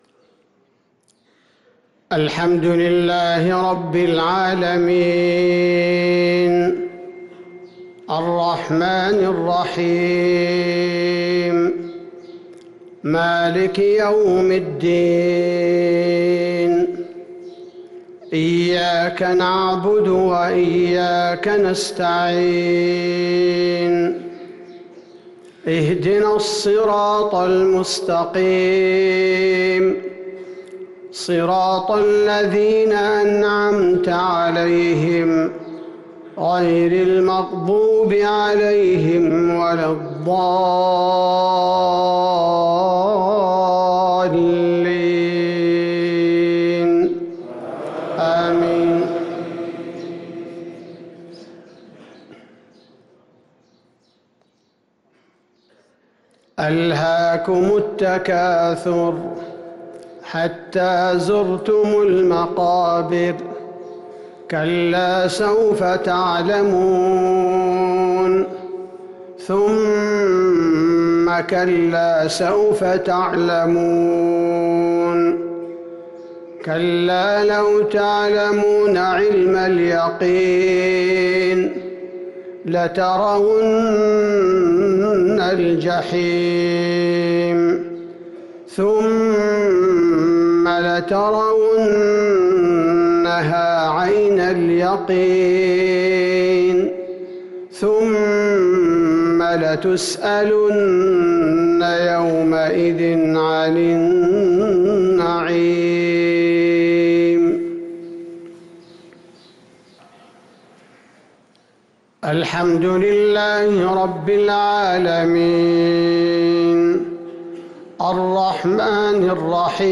صلاة المغرب للقارئ عبدالباري الثبيتي 10 رجب 1444 هـ
تِلَاوَات الْحَرَمَيْن .